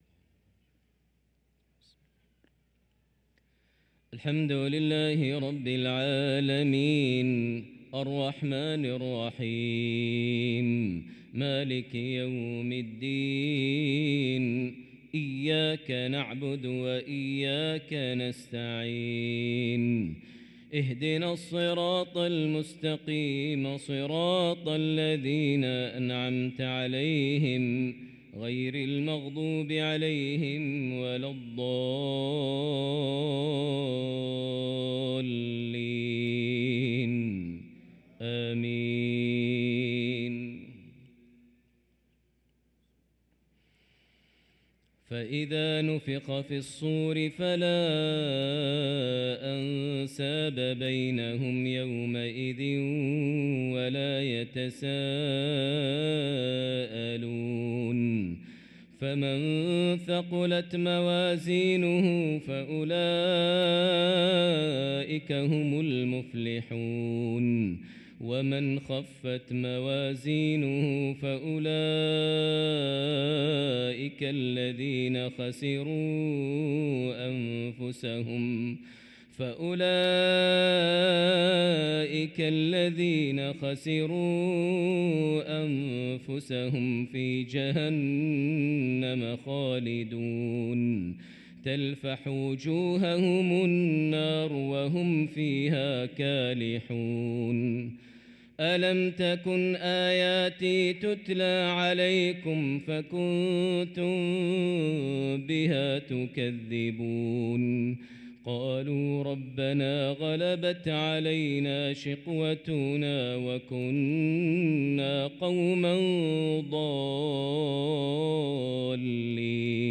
صلاة العشاء للقارئ ماهر المعيقلي 15 ربيع الآخر 1445 هـ
تِلَاوَات الْحَرَمَيْن .